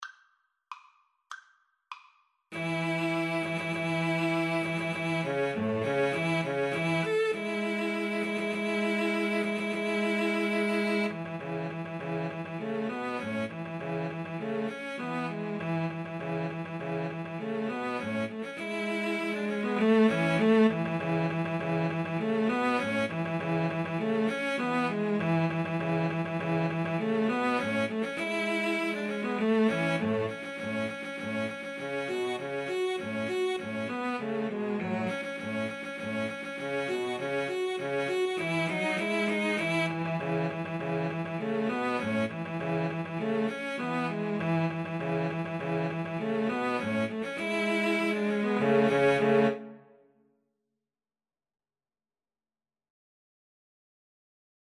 Free Sheet music for Cello Trio
A major (Sounding Pitch) (View more A major Music for Cello Trio )
Presto =200 (View more music marked Presto)